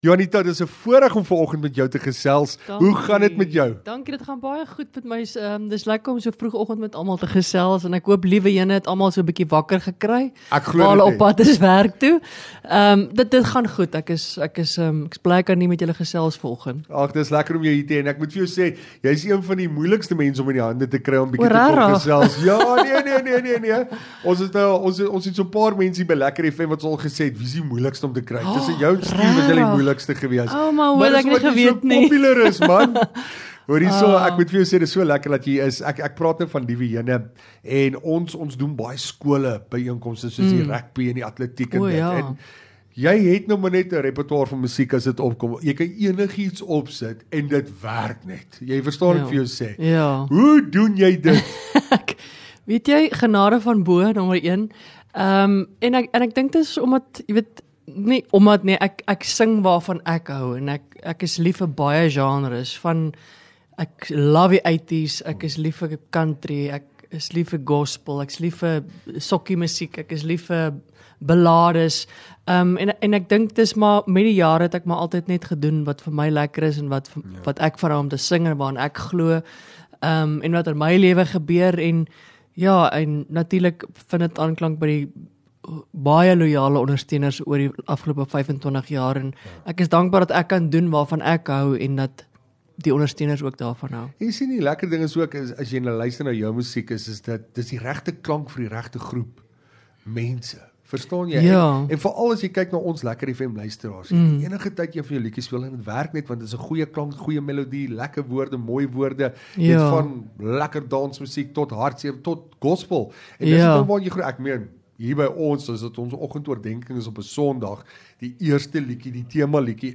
LEKKER FM | Onderhoude 13 May LEKKER KLETS